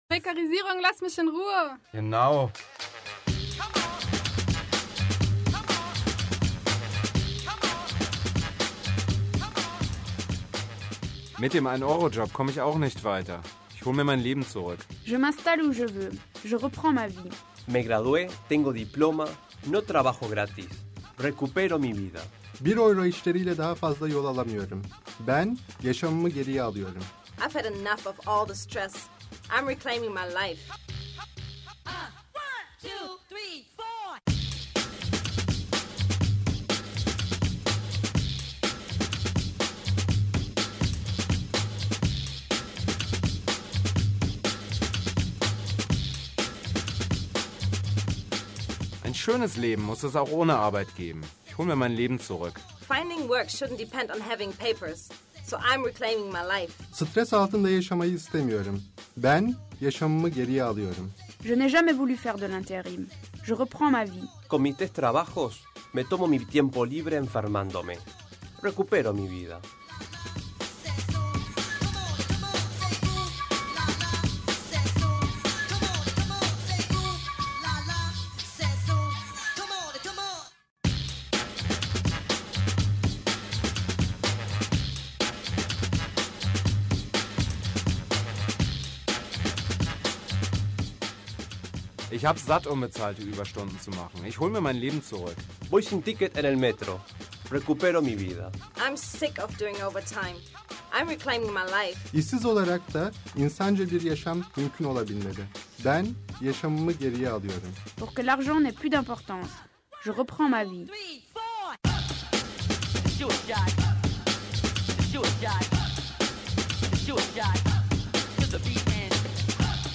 Wenn ihr schon mal in einen der mehrsprachigen Mayday-Jingles, die auf der Parade und vorab im Radio zu hören sind, reinhören möchtet, voilà:
Jingle